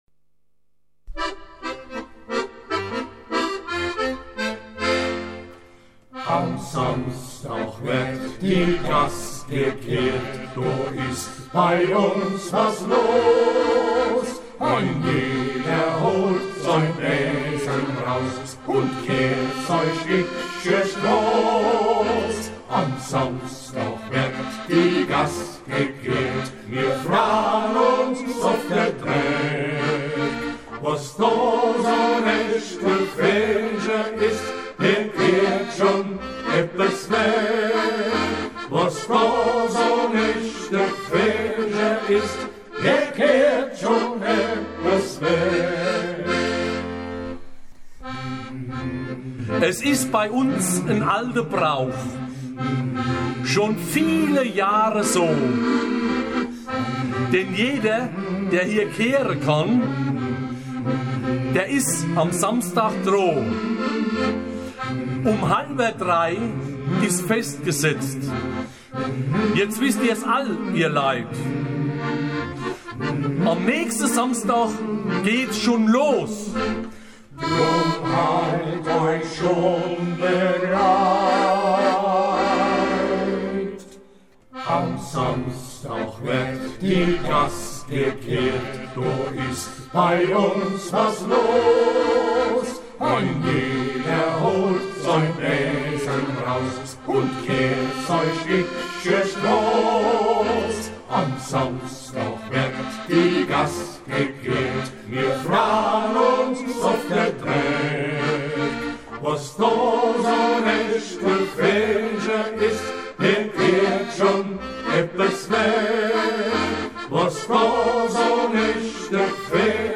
Wurde am Mundartabend, organisiert von den Mundartfreunden Südhessen, am Hessentag Bensheim von den Büttelborner Gasskehrern aufgeführt.